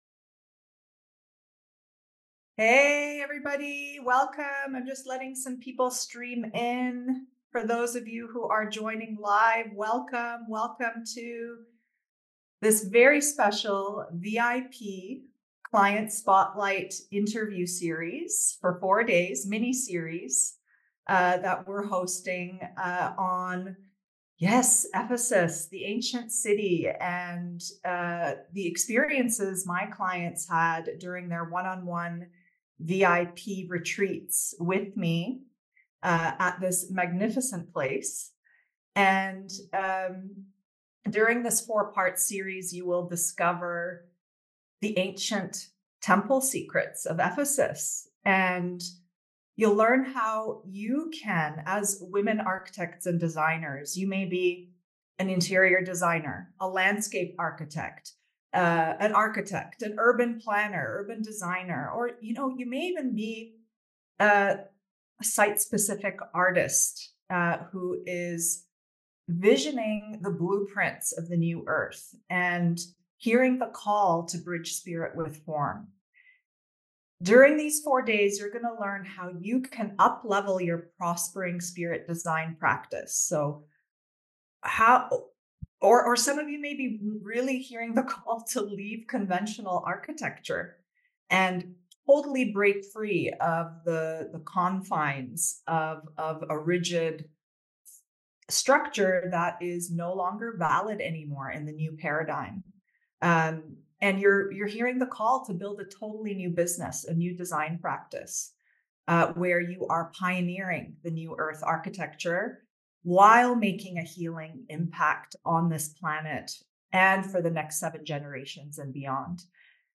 Discover Ancient Temple Secrets of Ephesus [Part 1] ~ VIP Client Spotlight Interview with Landscape Architect